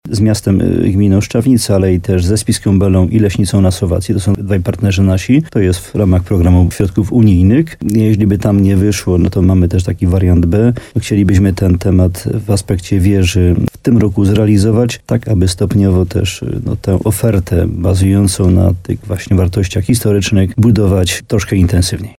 Wójt Jan Dziedzina wyjaśnia, że chodzi o w zdobycie pieniędzy poprzez aplikowanie na różne inwestycje turystyczne wspólnie ze słowackimi partnerami.